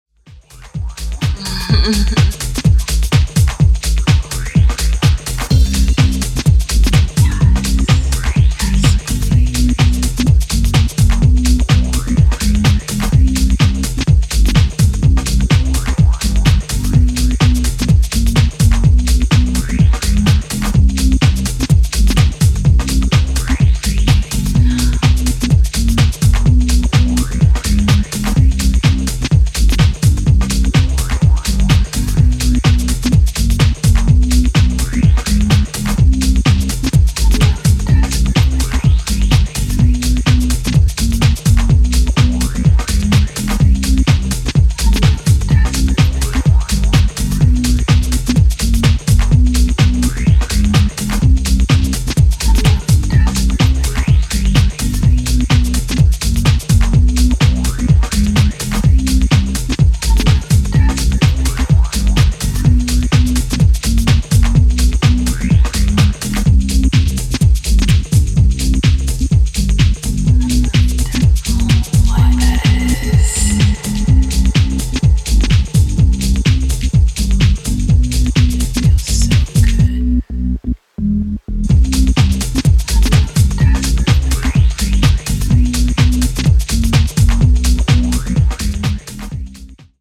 軽快な足回りのテック・ハウス4曲を収録
ベースラインが前面に伸びていく